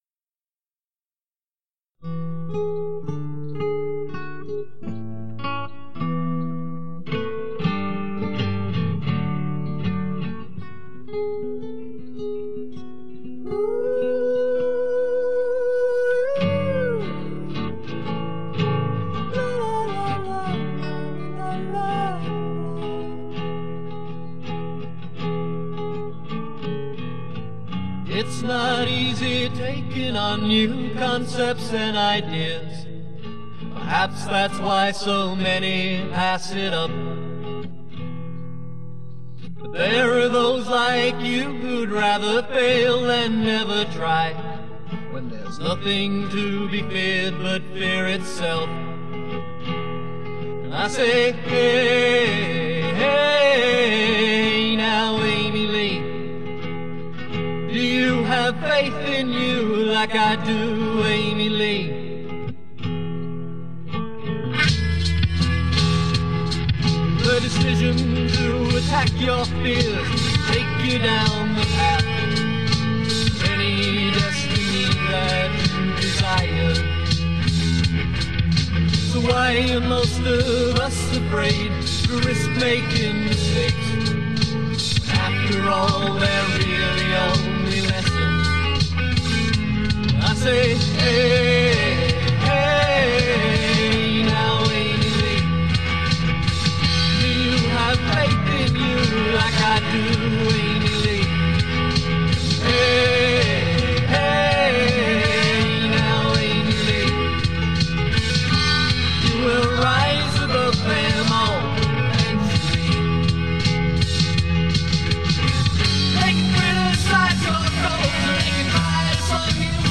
Guitar
recorded in Stafford Heights, Brisbane
voices/bass/keyboards/drums